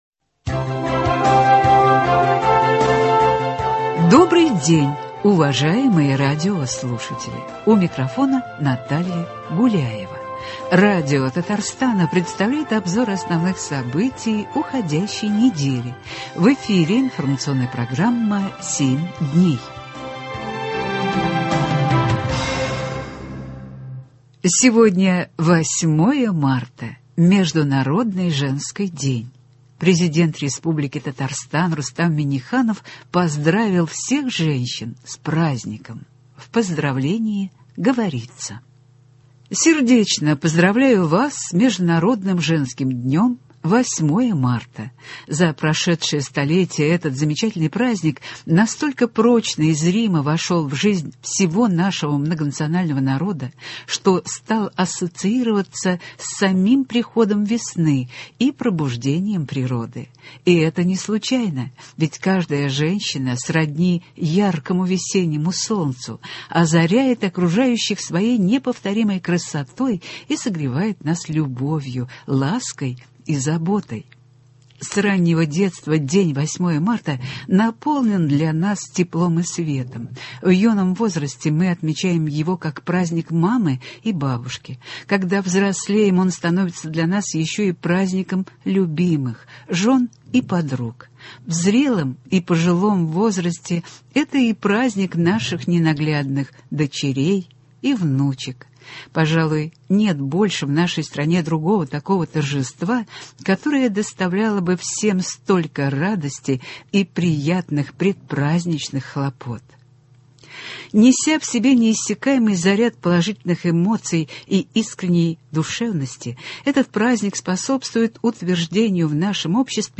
Обзор событий.